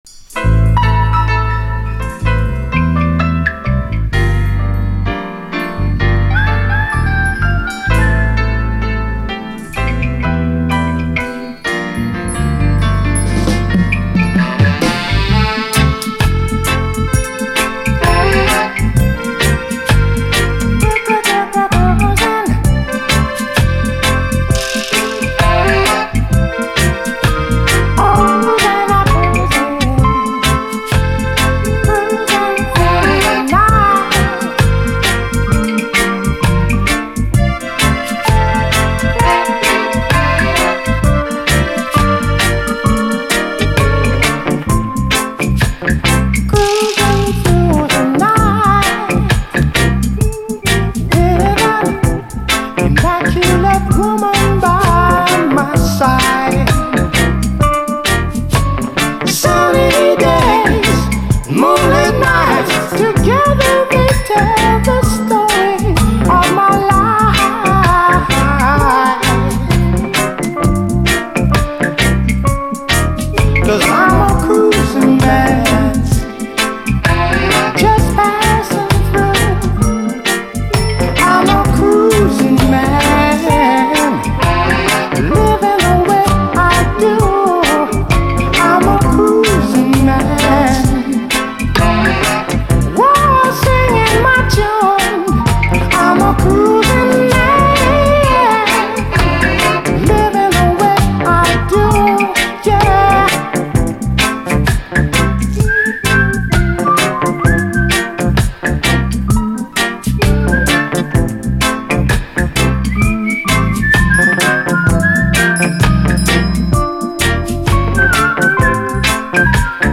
REGGAE
哀愁系レア・キラーUKラヴァーズ！
哀愁タップリ、ファルセット・ヴォーカル＆コーラス＆口笛が泣かせる一曲！